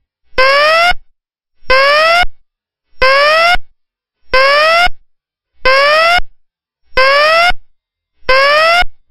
Fast whoop